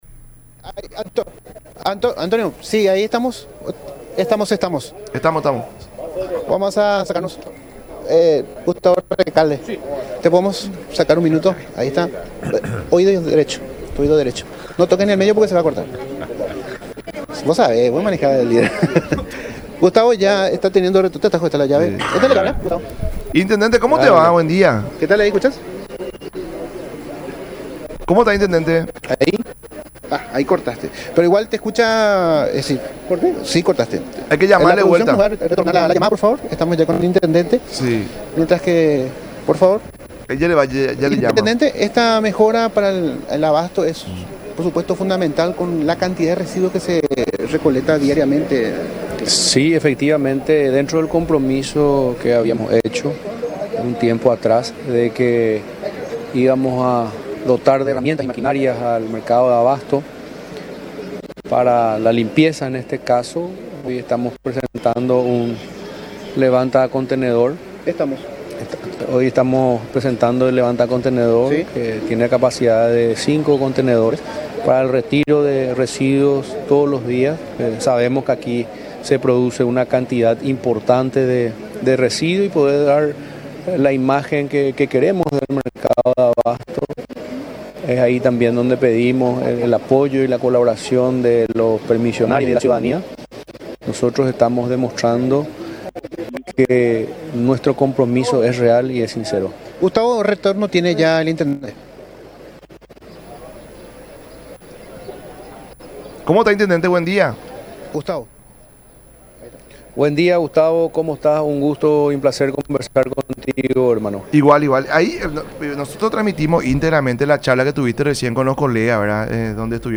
“Dentro del compromiso, dotamos un levanta contendedor para el retiro de residuos, todos los días, estamos demostrando nuestro compromiso real y sincero”, decía el intendente en charla con “La Mañana de Unión” por Unión TV y radio La Unión.